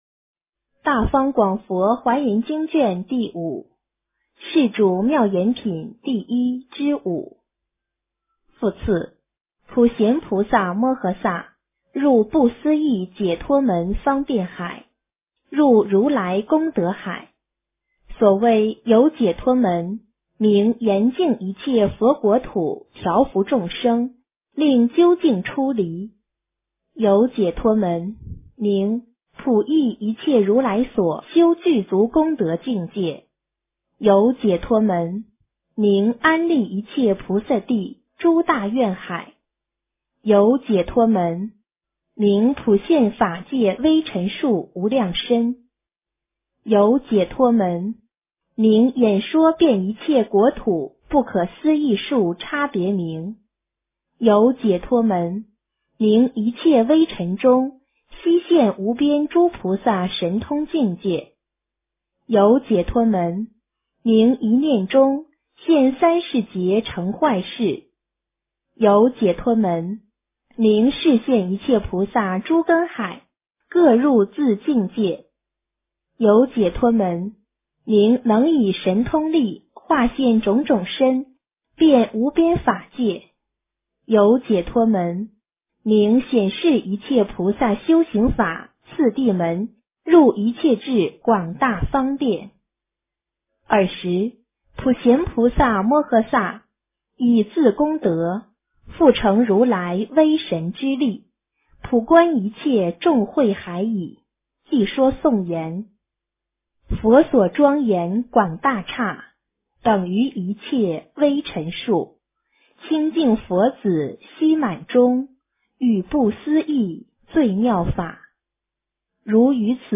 华严经05 - 诵经 - 云佛论坛